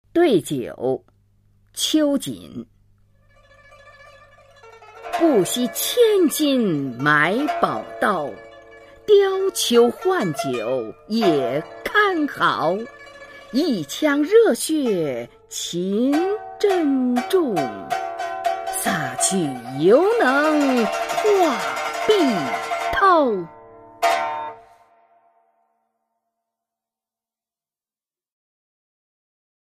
[清代诗词诵读]秋瑾-对酒 配乐诗朗诵